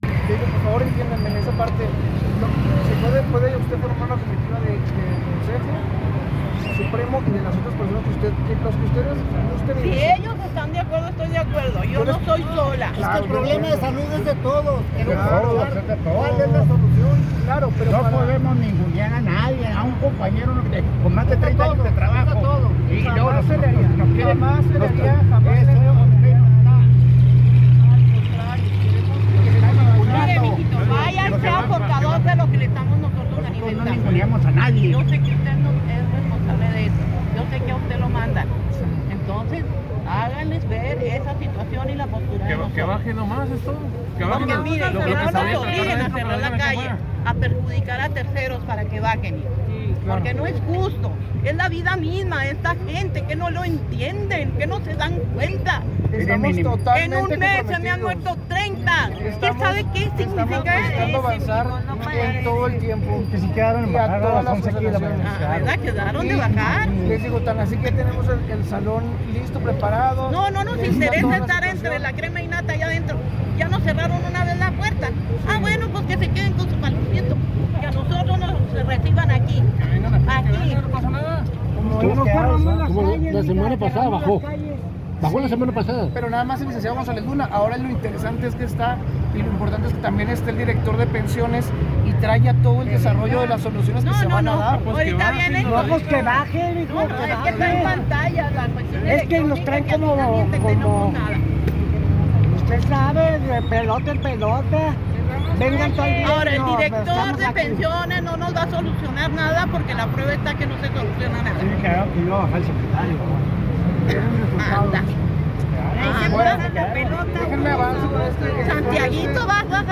Un grupo de maestras y maestros jubilados se reunió este miércoles, cerca de las 11 de la mañana, en la Plaza Hidalgo. Realizaron una manifestación pacífica para exigirle al gobierno pronta solución a la crisis en el servicio médico de Pensiones Civiles del Estado.
Micrófono en mano, alrededor de 100 personas solicitaron que el subsecretario general de Gobierno, Óscar González Luna, no los atienda en su oficina, como es habitual, sino que se presentara en persona en la Plaza, frente a todos los manifestantes, para que conociera de primera mano las inquietudes de quienes trabajaron toda su vida por la educación en Chihuahua.